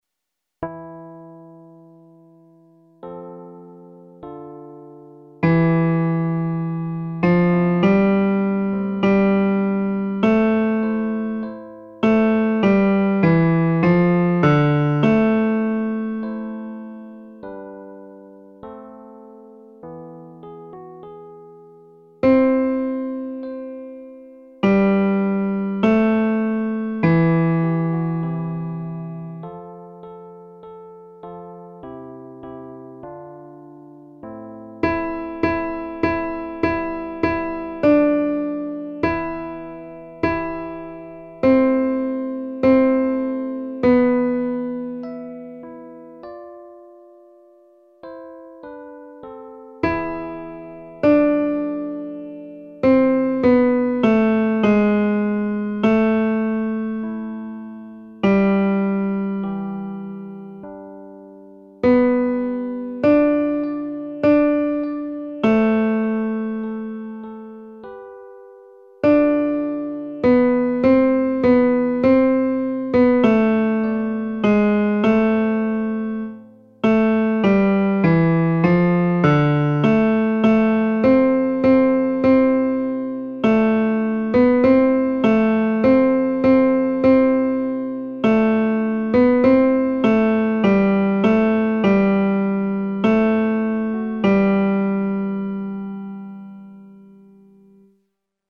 Audios para estudio (MP3)
Tenor